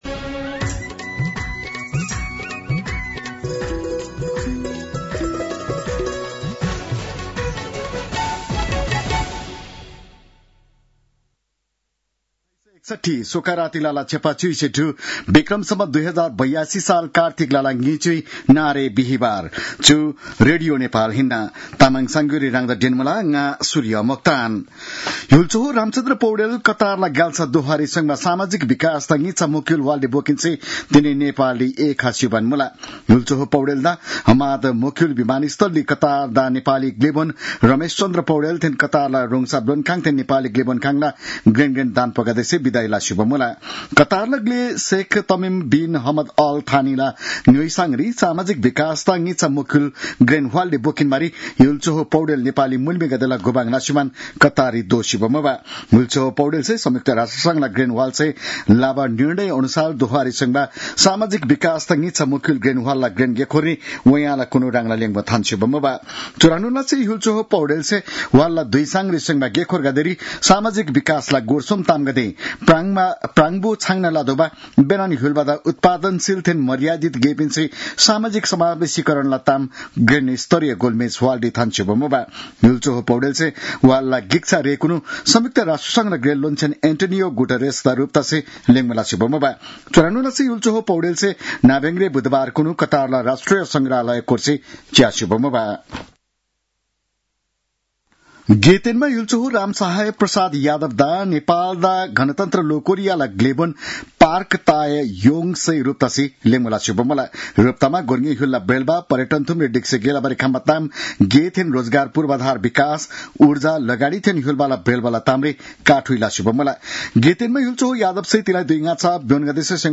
तामाङ भाषाको समाचार : २० कार्तिक , २०८२